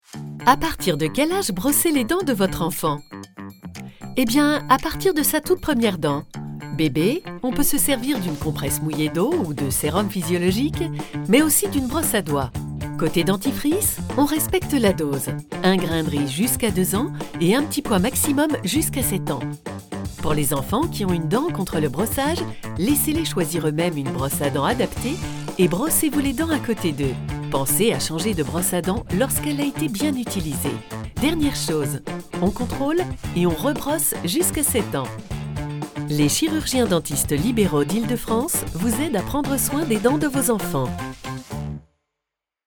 Voix pour une série de vidéos sur la santé bucco-dentaire des enfants destinés aux parents. Voix enjouée, complice et souriante.